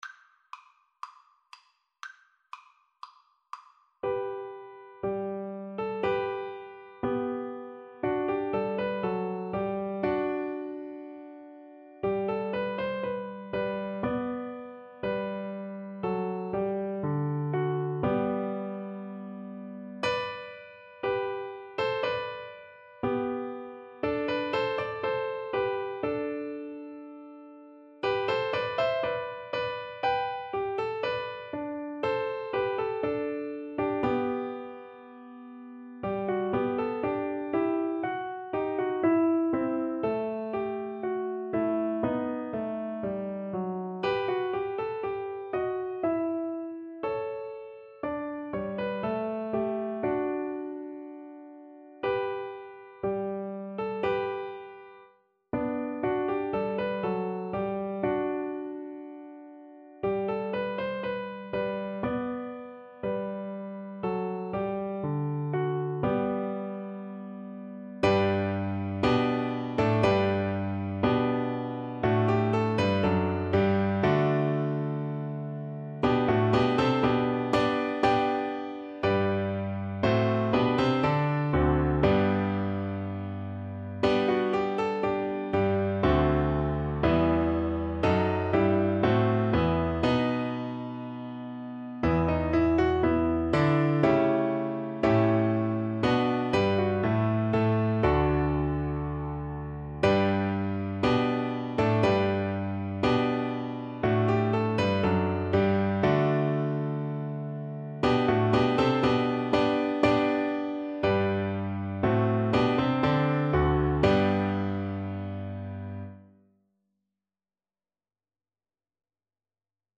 4/4 (View more 4/4 Music)
Moderato (=120)
Classical (View more Classical Flute Music)